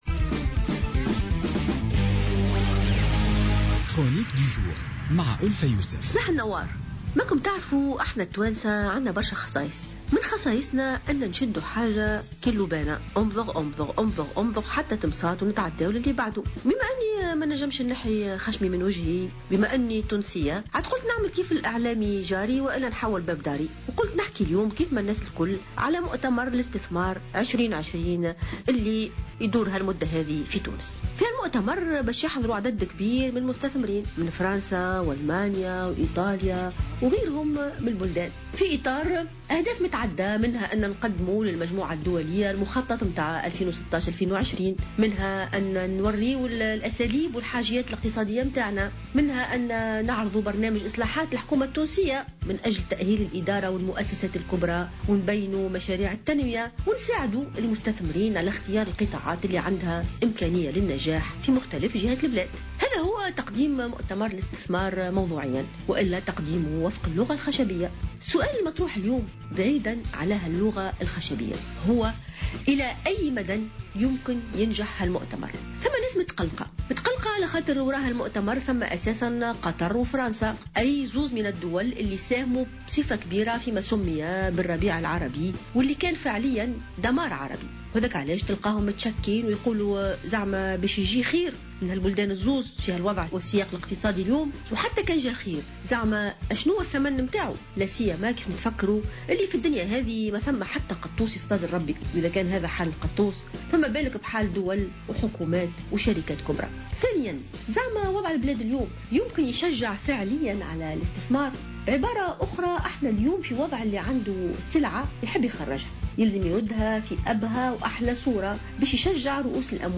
قالت الجامعية ألفة يوسف في افتتاحية اليوم الخميس 1 ديسمبر 2016 إن التفاؤل يبقى قائما على الرغم من الشكوك التي رافقت عملية ضخ الأموال المعلن عنها خلال الندوة الدولية للاستثمار " تونس 2020 " و الأسئلة المطروحة حول خلفياتها ونجاعتها.